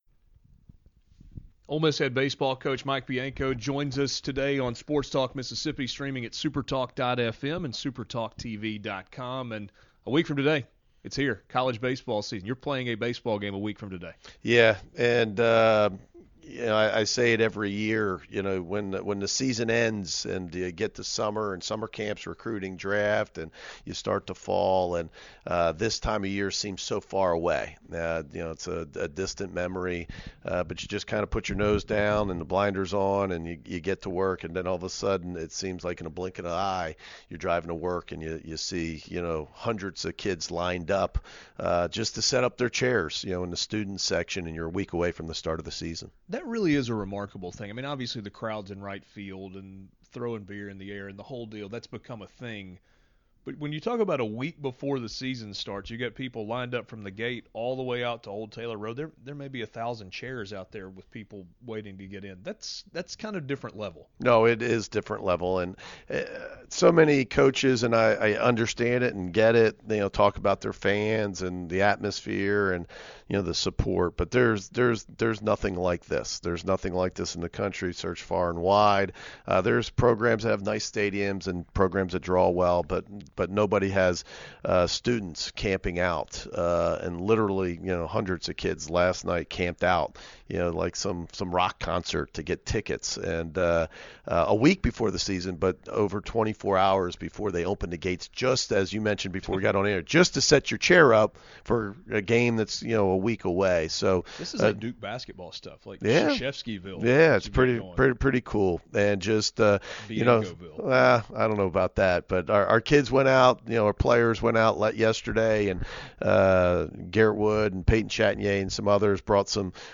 one on one conversation